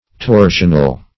Torsional \Tor"sion*al\, a.